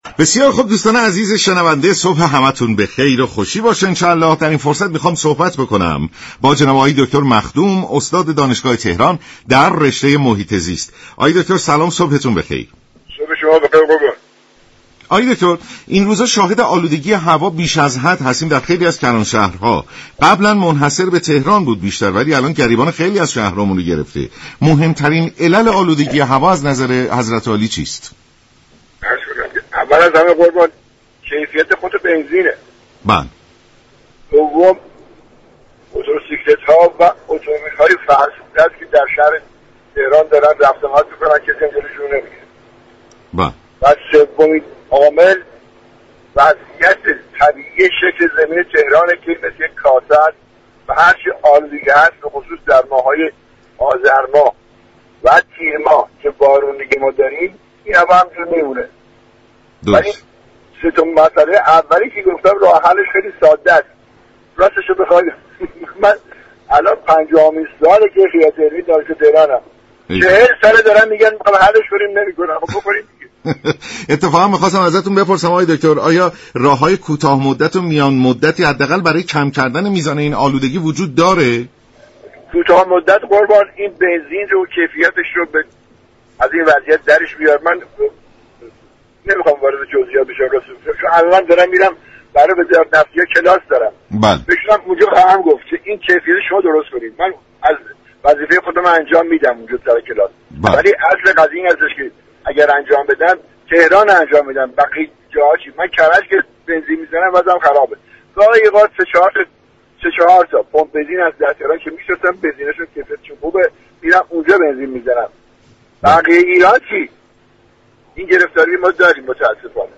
استاد رشته محیط زیست دانشگاه تهران در گفت و گو با رادیو ایران گفت: كیفیت پایین بنزین تولید شده، رفت و آمد خودروهای فرسوده در سطح شهر و شكل طبیعی شهر تهران سه عامل مهم در بروز آلودگی هوا است.